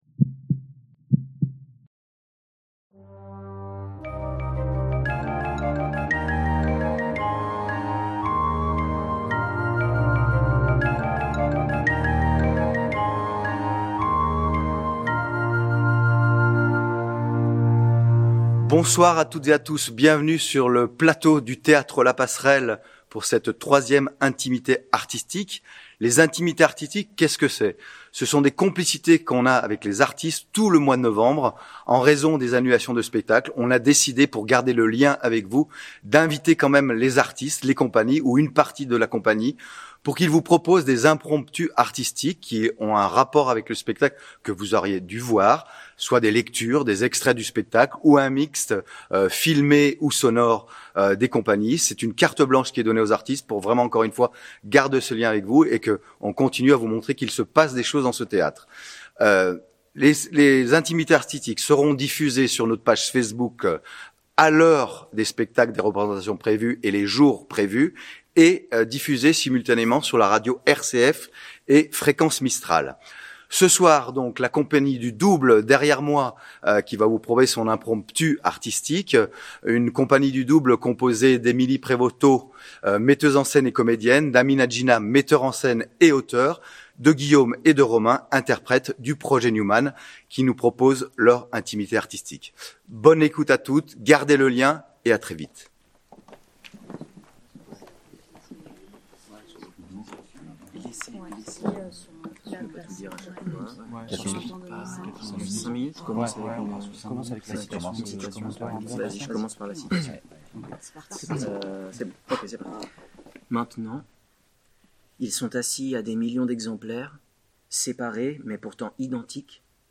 Il est question de notre rapport aux médias et notamment à la télévision et à la radio à travers les écrits des Günther Anders, philosophe allemand auteur de L’Obsolescence de l’homme, ouvrage qui a fortement inspiré la création de Projet Newman. La prise sonore est de nos confrères de RCF.